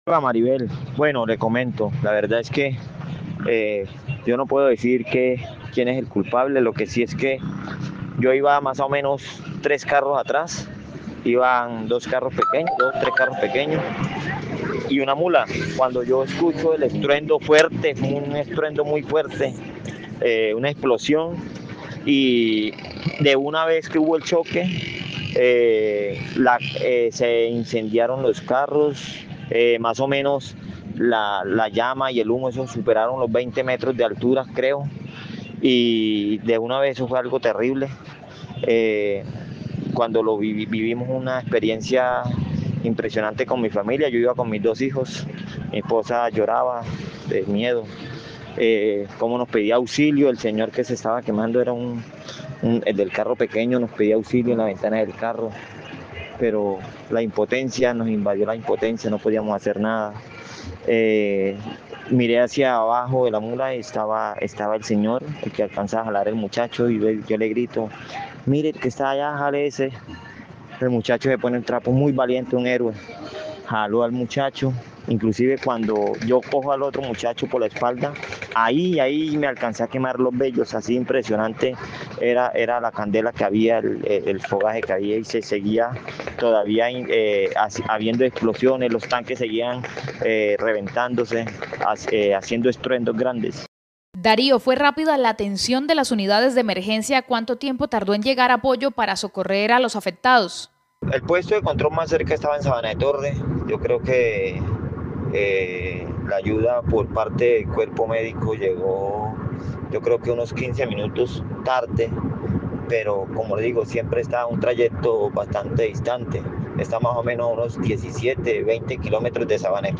VOZ TESTIGO ACCIDENTE